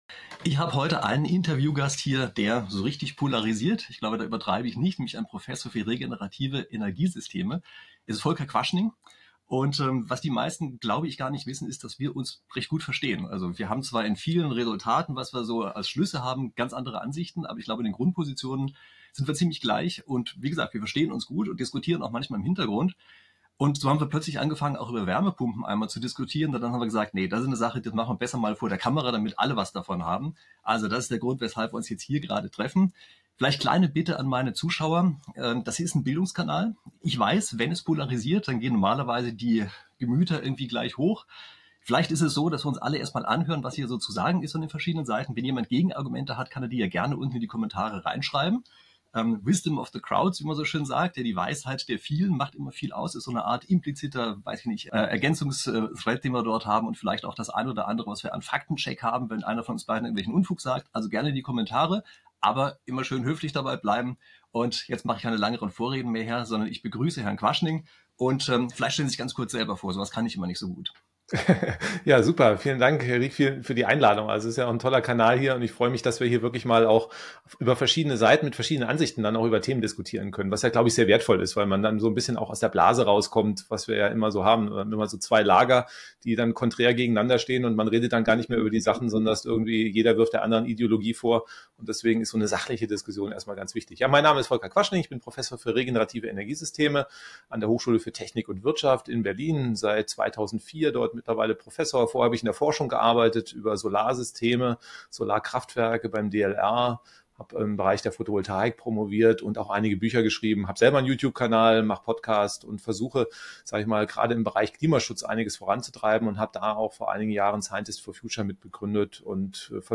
216. Fragen zur Wärmepumpe: Prof. Quaschning im Gespräch - Prof Rieck ~ Prof. Dr. Christian Rieck Podcast